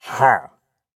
掠夺者：咕哝
空闲时随机播放这些音效
Minecraft_pillager_idle3.mp3